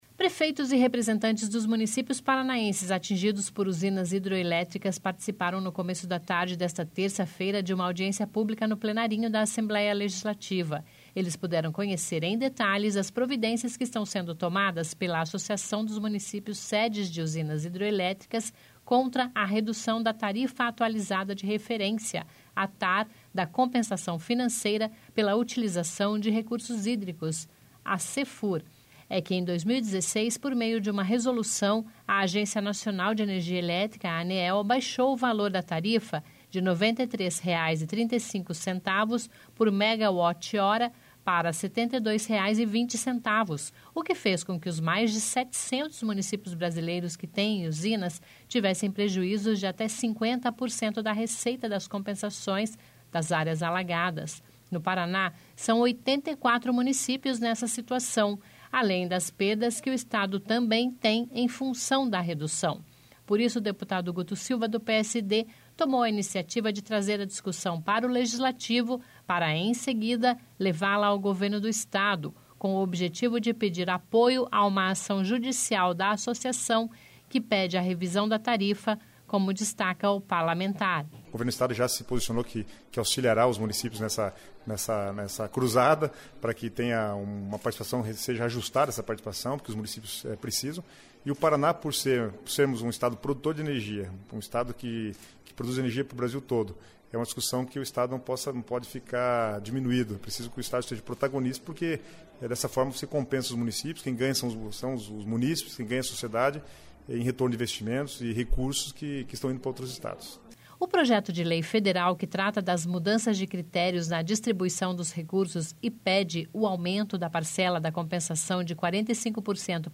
(Descrição do áudio))Prefeitos e representantes dos municípios paranaenses atingidos por usinas hidroelétricas participaram, no começo da tarde desta terça-feira (28) de uma audiência pública no Plenarinho da Assembleia Legislativa.